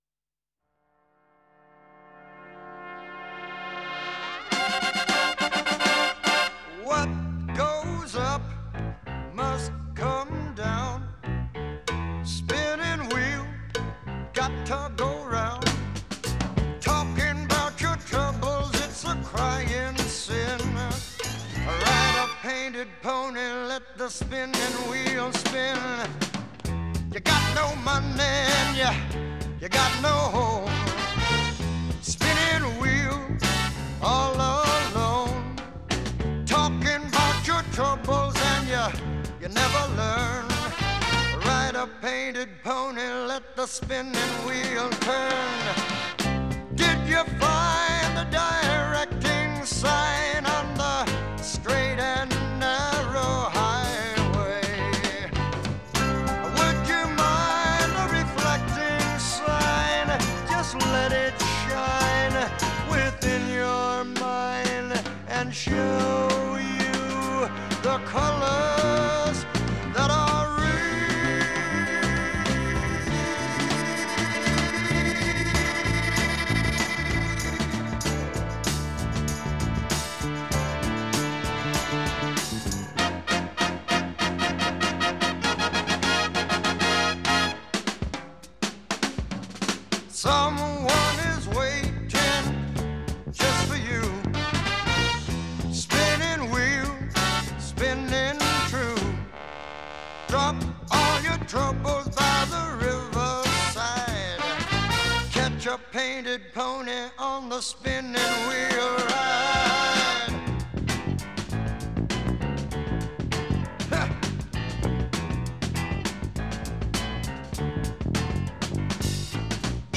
Жанр: Jazz-Rock